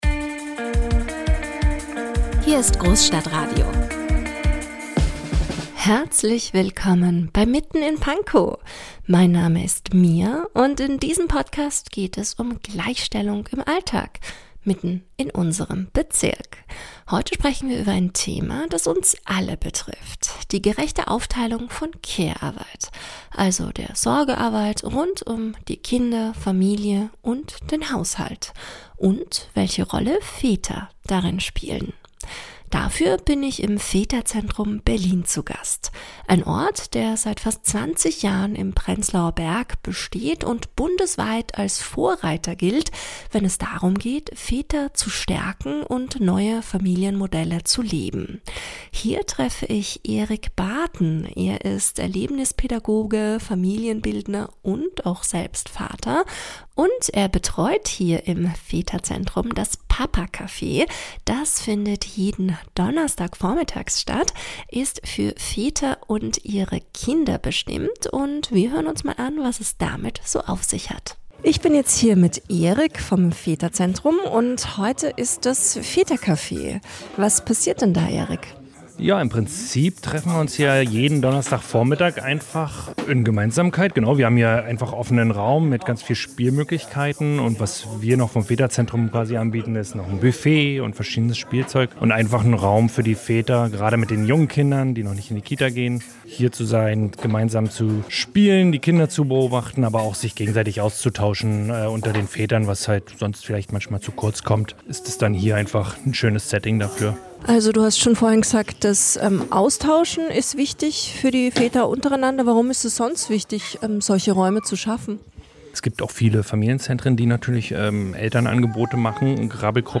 Gemeinsam entsteht ein vielstimmiges Bild davon, wie Vaterschaft heute gelebt wird – zwischen Tradition, Veränderung und dem Wunsch nach echter Partnerschaftlichkeit.